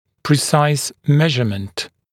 [prɪ’saɪs ‘meʒəmənt][при’сайс ‘мэжэмэнт]точный размер, точное измерение